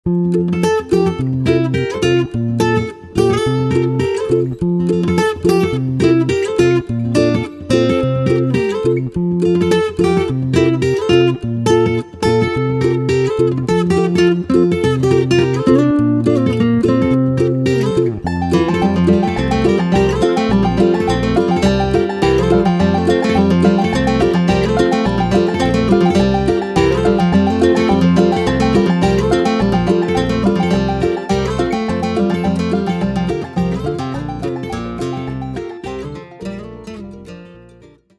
bluegrass inspired treatment